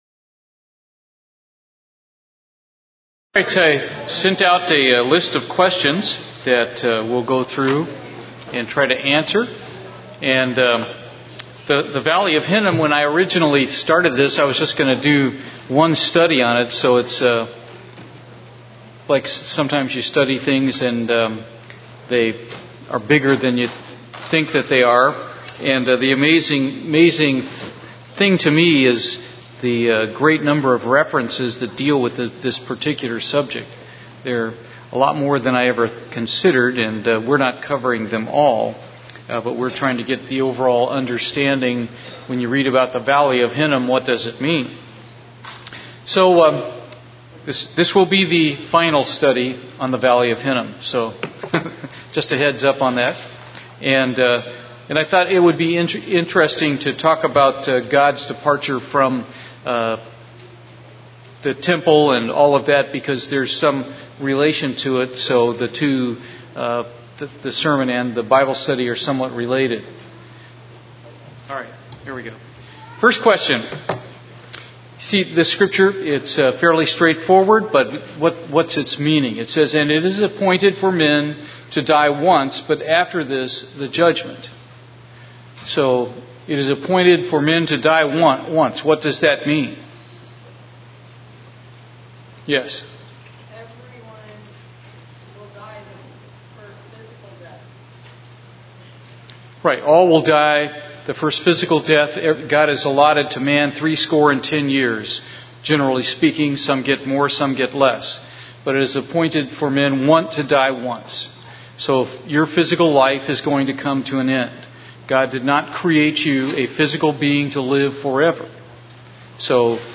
He goes through a series of questions on the final judgement which he sent out to the congregation in advance for us to study and be ready to comment on during the study.
Given in Houston, TX